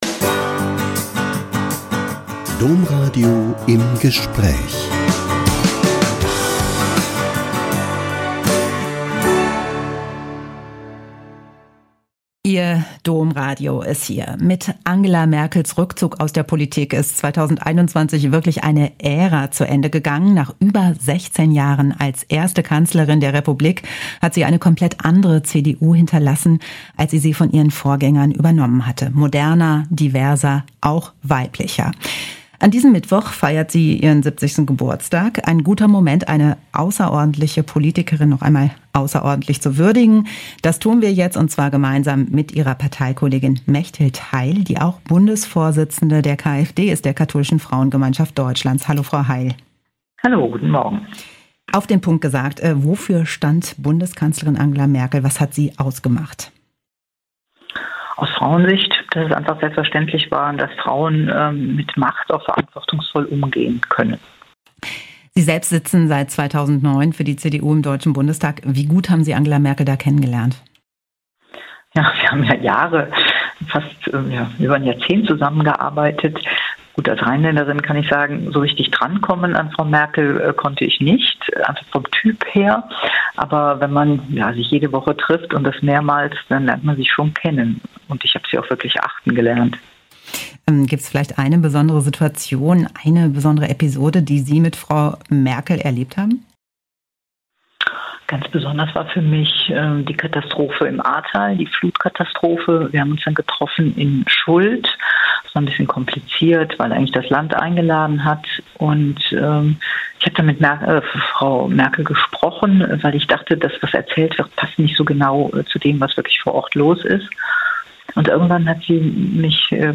Bundestagesageordnete würdigt Merkel zu 70. Geburtstag - Ein Interview mit Mechthild Heil (Bundesvorsitzende der Katholische Frauengemeinschaft Deutschlands, kfd) ~ Im Gespräch Podcast